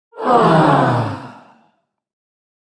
Crowd-aww-sound-effect.mp3